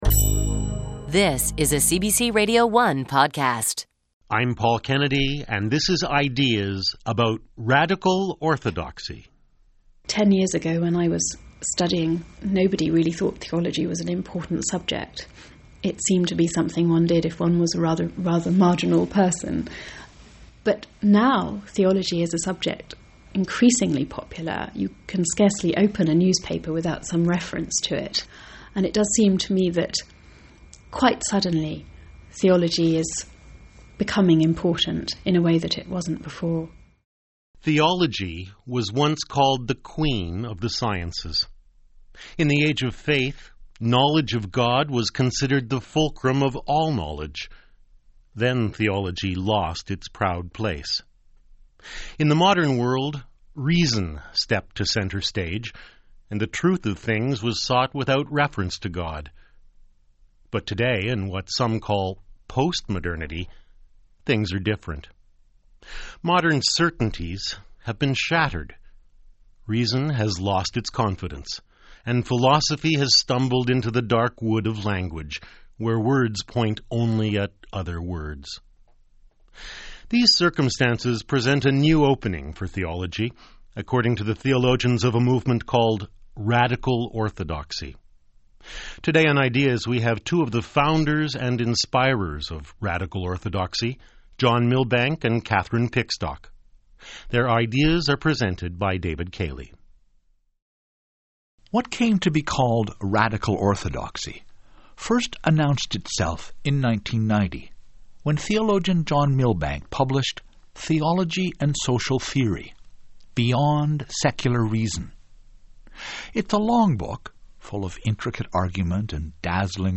CBC-podcast-on-Radical-Orthodoxy.mp3